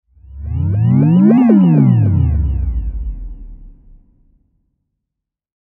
Futuristic-deep-sci-fi-whoosh-sound-effect.mp3